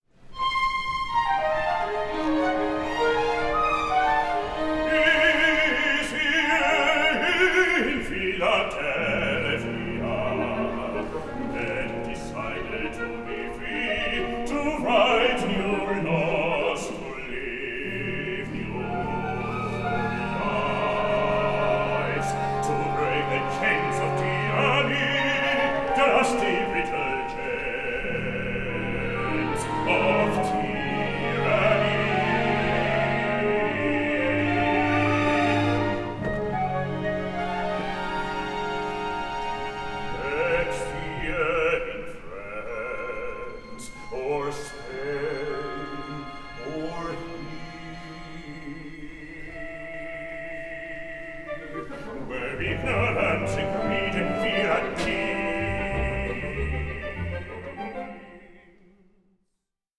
Recitativo a parte